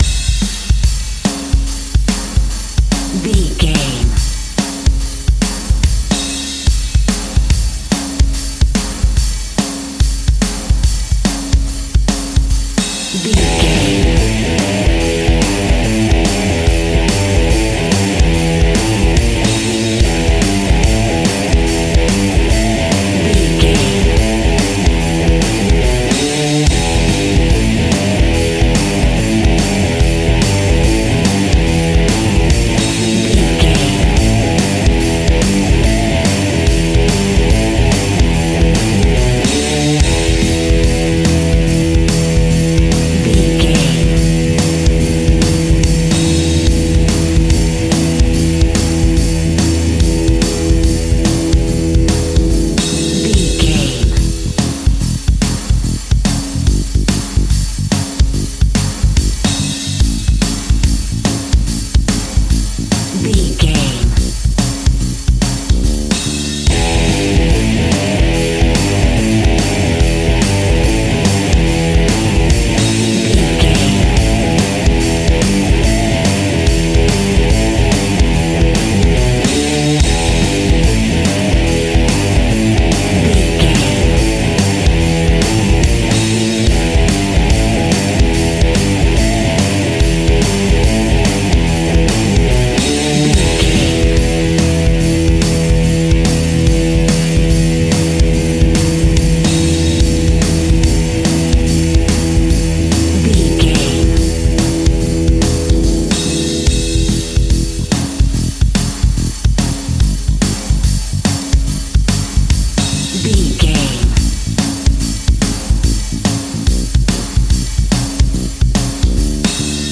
Epic / Action
Aeolian/Minor
heavy metal
blues rock
distortion
instrumentals
rock guitars
Rock Bass
heavy drums
distorted guitars
hammond organ